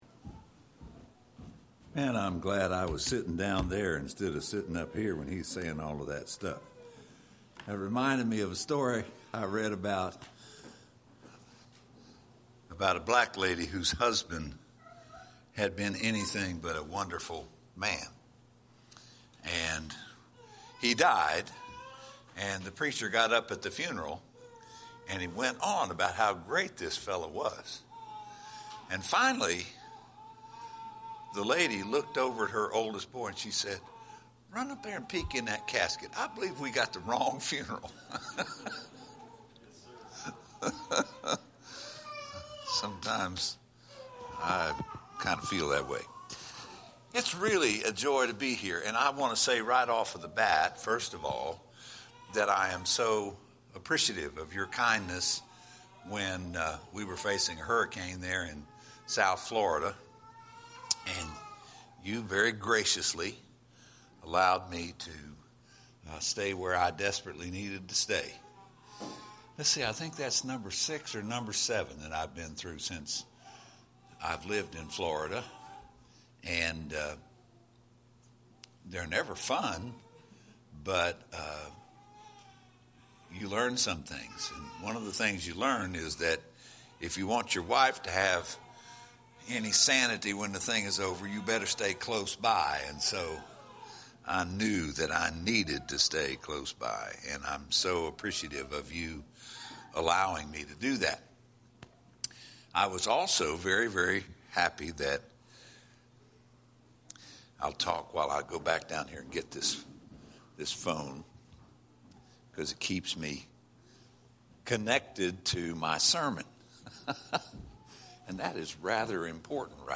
A sermon
Series: Spring Revival 2025